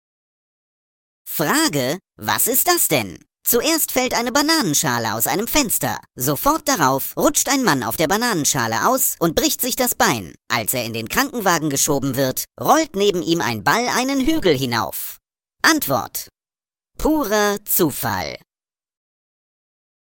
Vorgetragen von unseren attraktiven SchauspielerInnen.
Comedy , Unterhaltung , Kunst & Unterhaltung